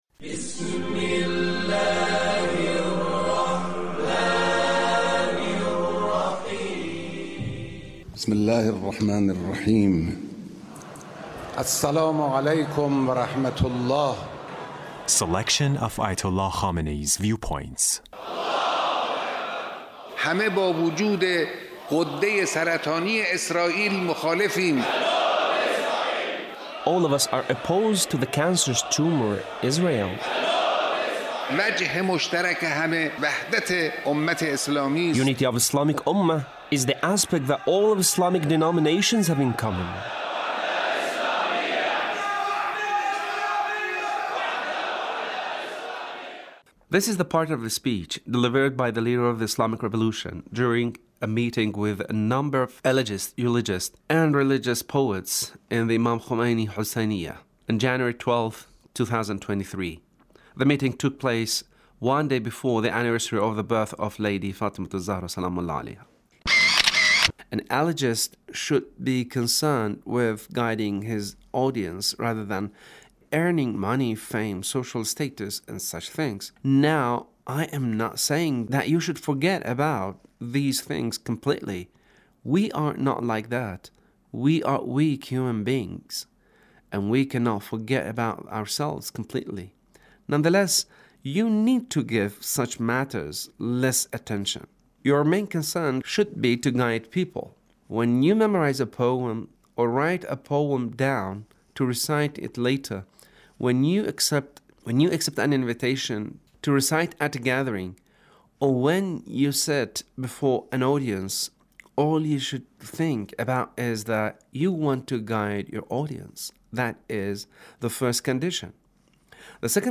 Leader's Speech (1633)
Leader's Speech meeting with Eulogists